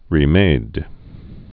(rē-mād)